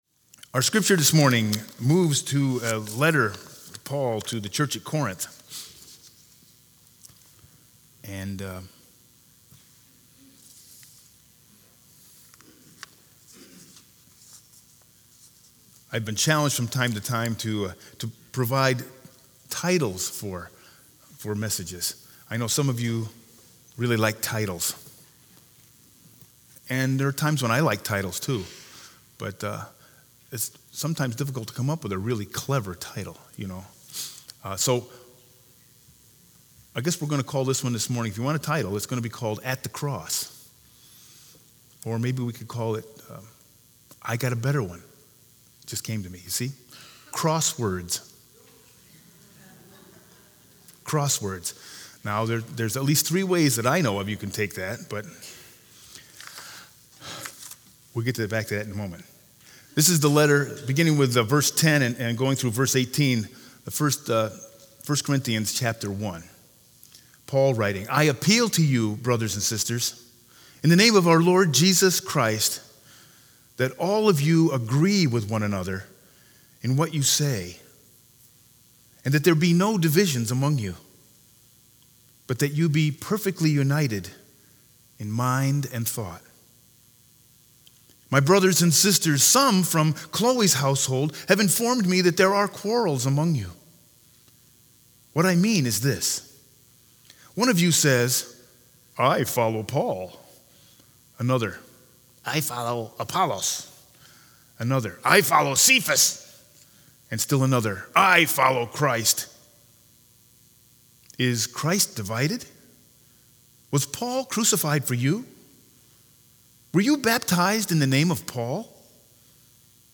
Sermon 1-26-20 with Scripture Lesson 1 Corinthians 1_10-18